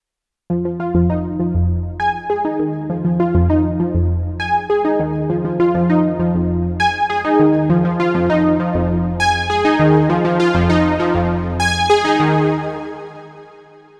Novation Peak – Klangbeispiele
novation_peak_test__arpeggio_8.mp3